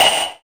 Index of /90_sSampleCDs/Optical Media International - Sonic Images Library/SI2_SI FX Vol 7/SI2_Gated FX 7